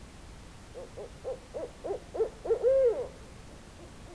Additionally I have plotted the power spectra of a Barred owl recorded (Fig 4) with my Evistr V508 while utilizing the two-boundary rig. The frequency signature of the owl is centered at 536 Hz where the two-boundary gain is higher than that of a dish.
Listen: Chouette Rayeé (file un-normalized)